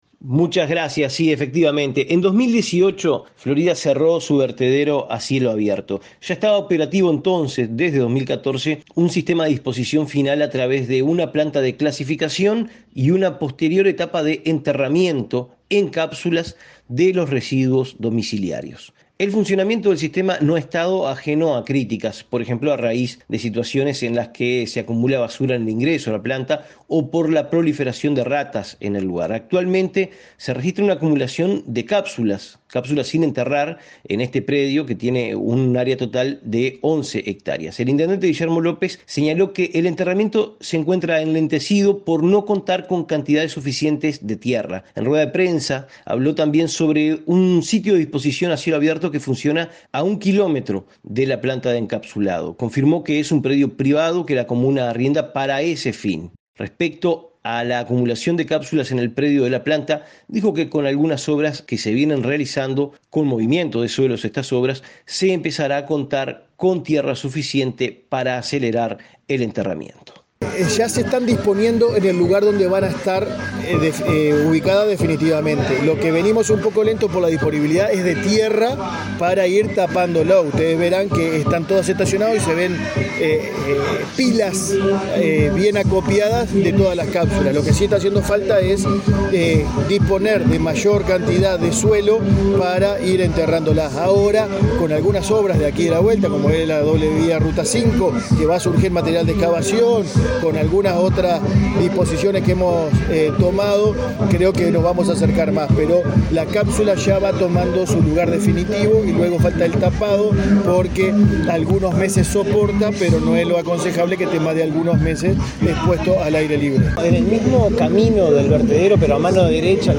El intendente Guillermo López señaló que el enterramiento se encuentra enlentecido por no contar con cantidades suficientes de tierra. En rueda de prensa, habló también sobre un sitio de disposición a cielo abierto que funciona a un kilómetro de la planta de encapsulado.
Escuchar el informe completo del corresponsal